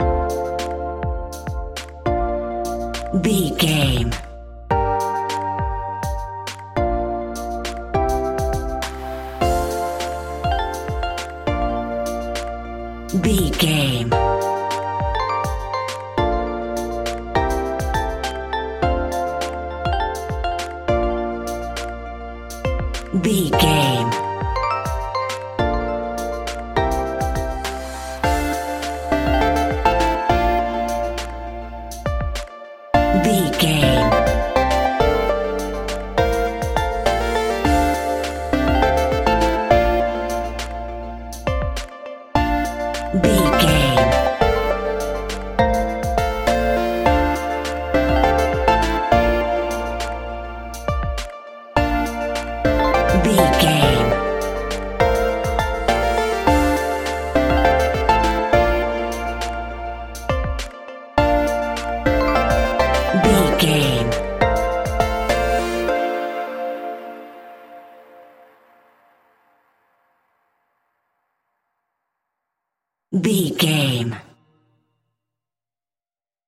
Aeolian/Minor
D
hip hop
chilled
laid back
hip hop drums
hip hop synths
piano
hip hop pads